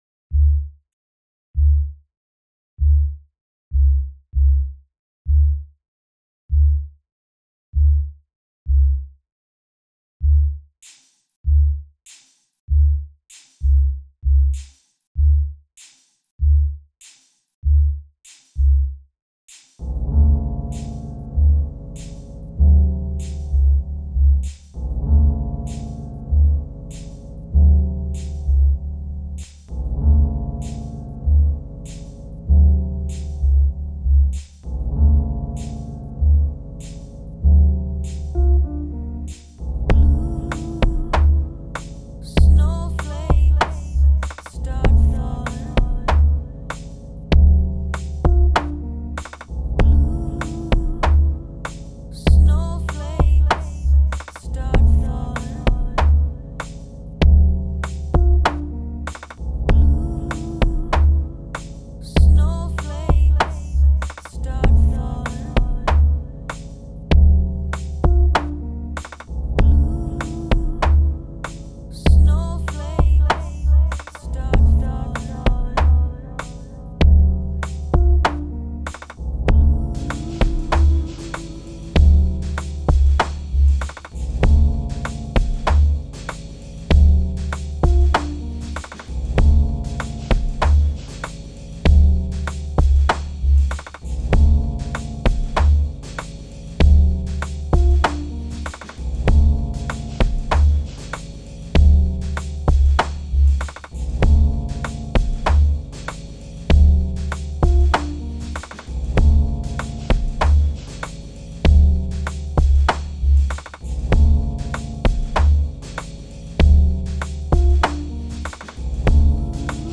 My first time using Koala to make anything, added some Jann Arden vocals because someone received a novel of hers as a gift
it sounds like a trip down a dark road, and those vocals came out really clean!